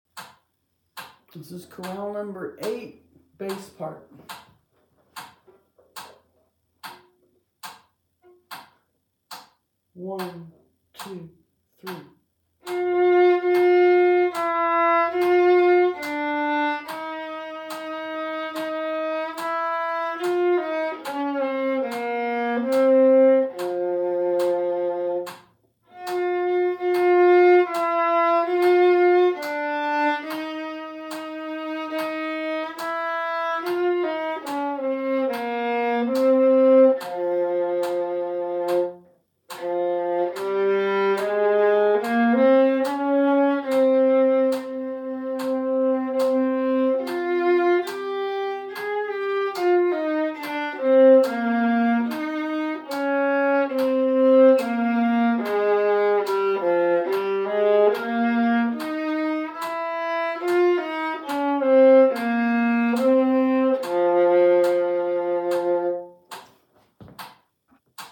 Chorale No 8, Bass